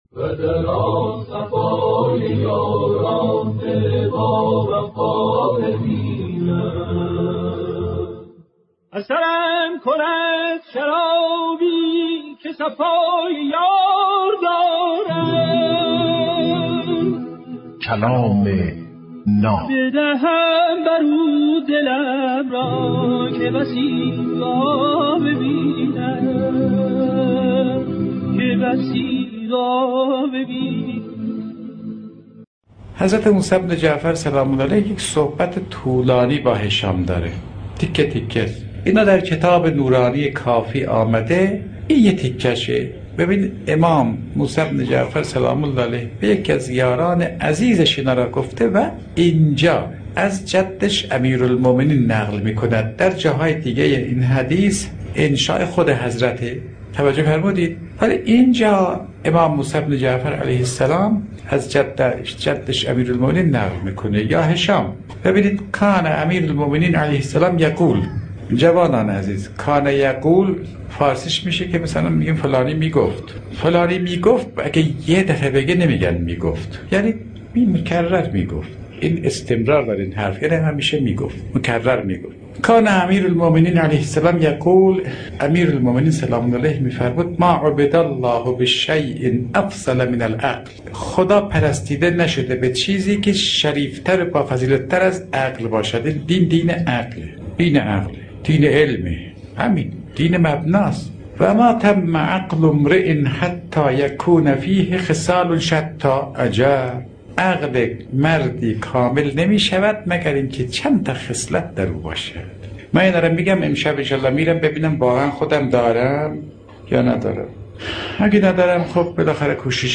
کلام ناب برنامه ای از سخنان بزرگان است که هر روز به مدت 8دقیقه پخش می شود